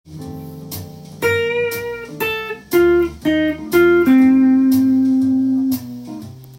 譜面通り弾いてみました
F7コードの構成音で作られています。
音符は２分音符、４分音符、８分音符と